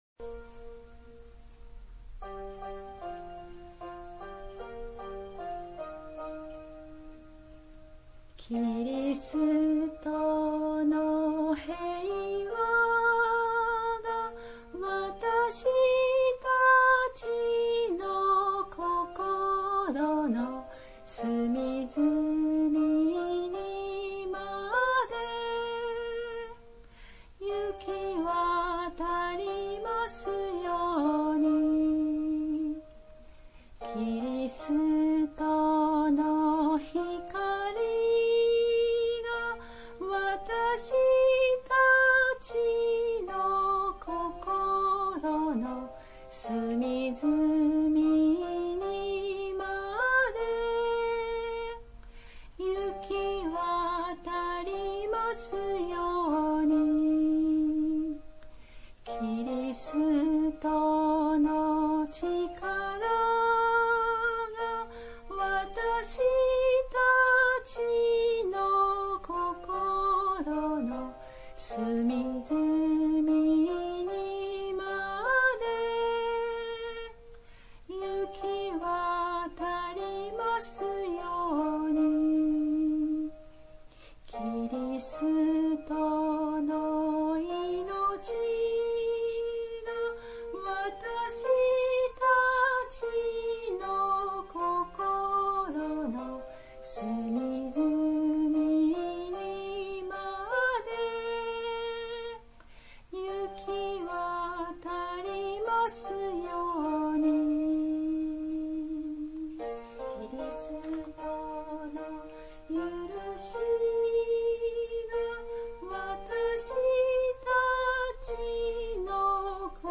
礼拝 応答唱